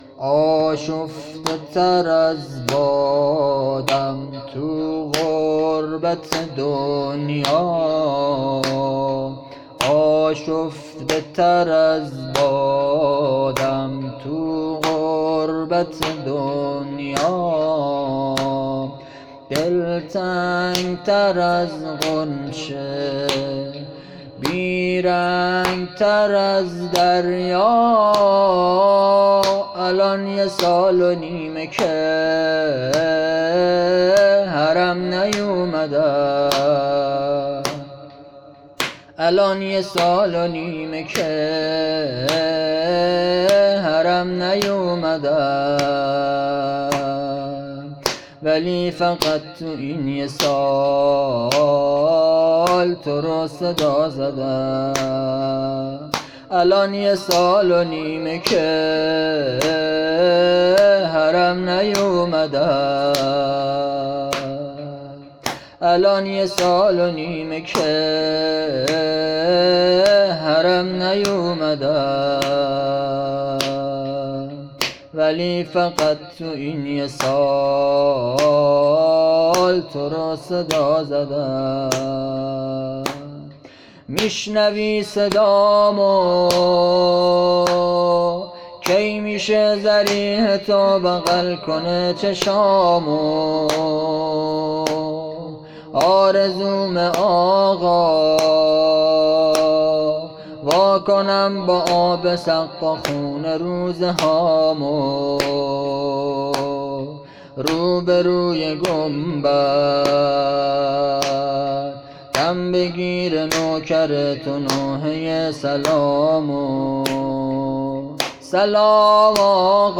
آشفته تر از بادم توو غربت دنیا - شب 1 محرم 1400
زمینه احساسی